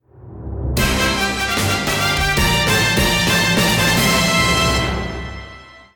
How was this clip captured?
Higher quality rip from the Wii U version.